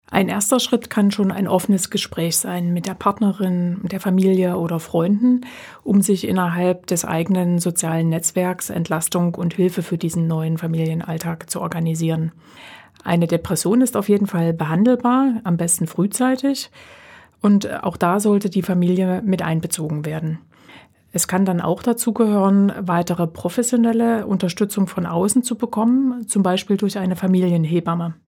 Radio O-Töne